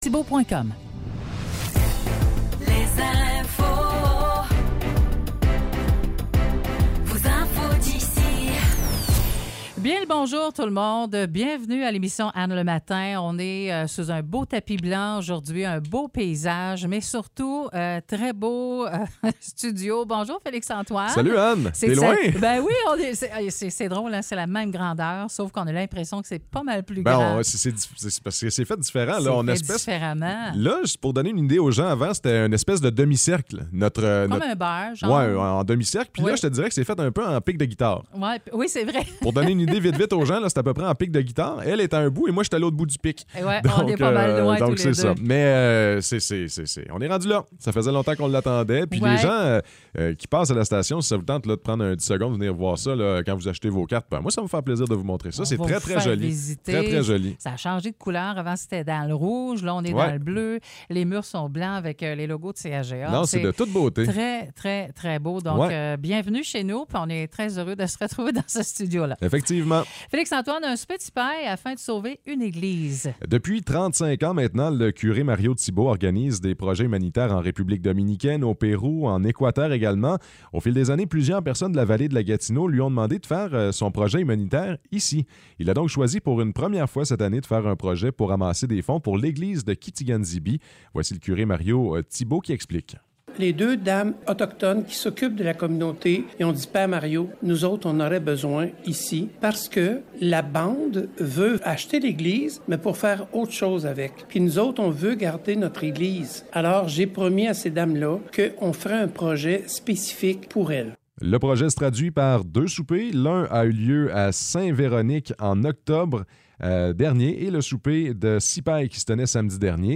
Nouvelles locales - 4 décembre 2023 - 9 h